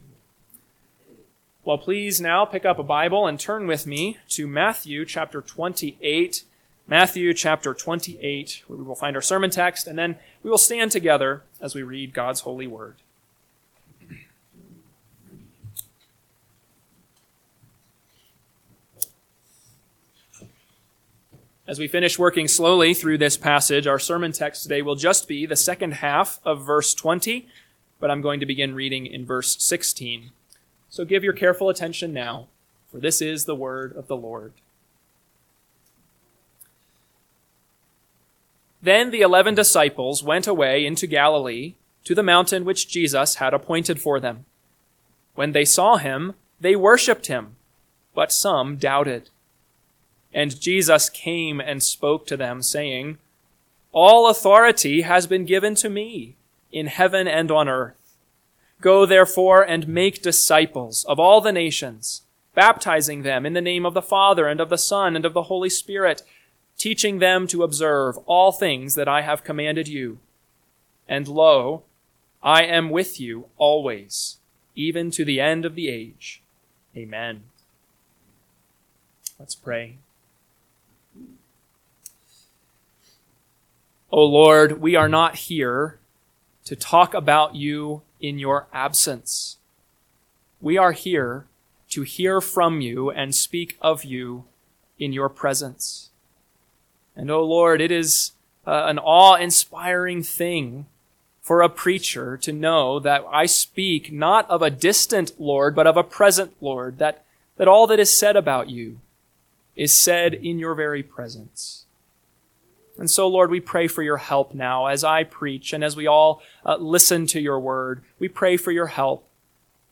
AM Sermon – 6/22/2025 – Matthew 28:20b – Northwoods Sermons